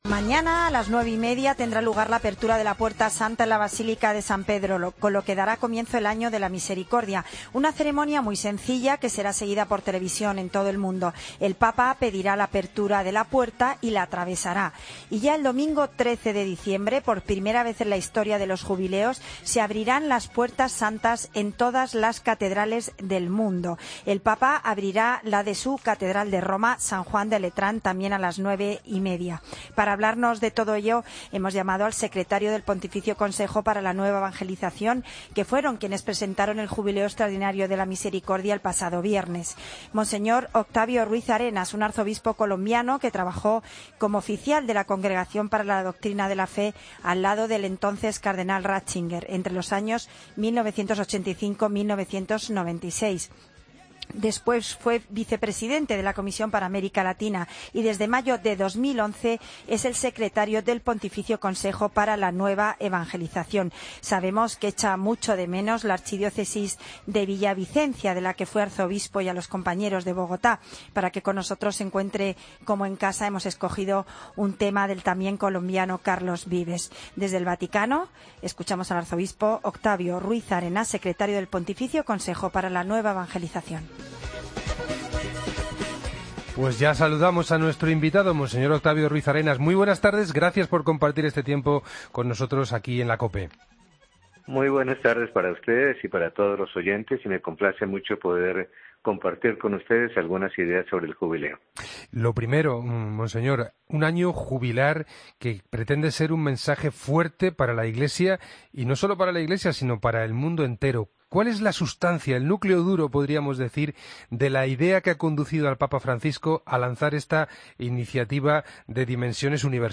Escucha la entrevista al arzobispo Octavio Ruíz Arenas